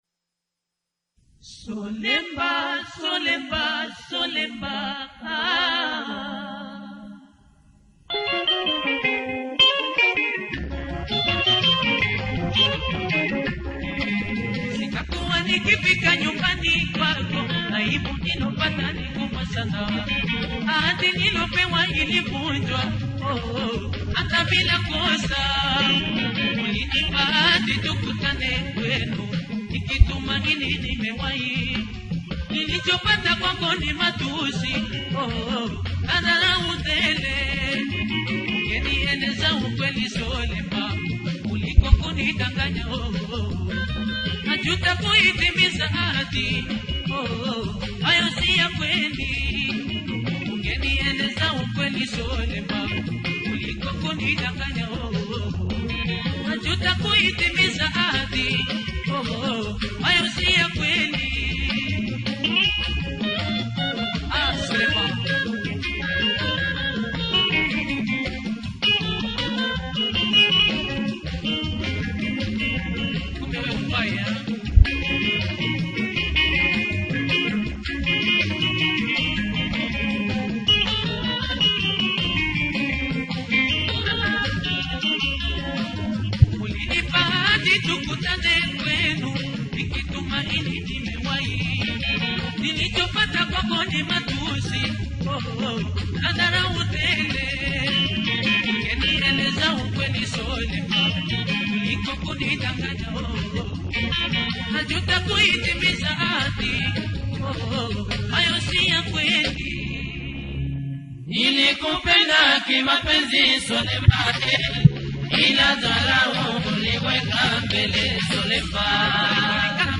Huyu jamaa alikuwa na sauti tamu sana.